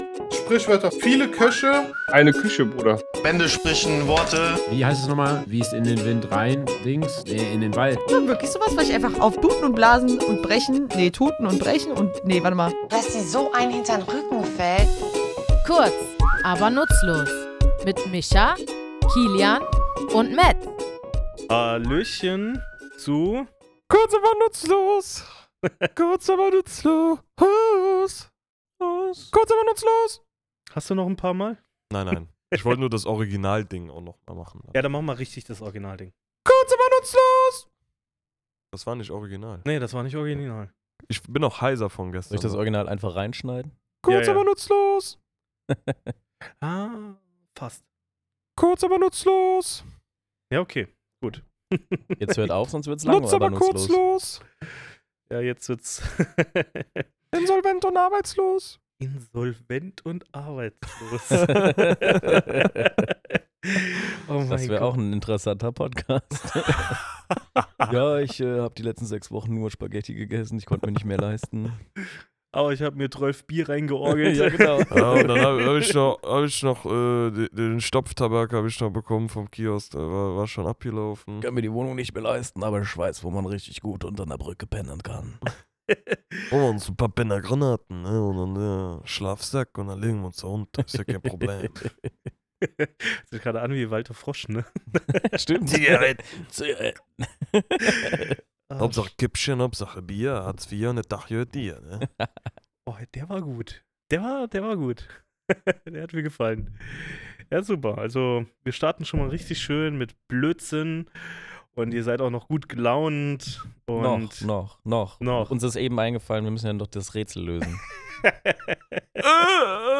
Wir, drei tätowierende Sprachfans, tauchen in unserem Tattoostudio tief in die Geschichte dieser Redensart ein – von der Antike bis in die heutige Zeit.